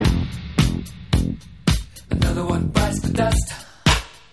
• Rock Ringtones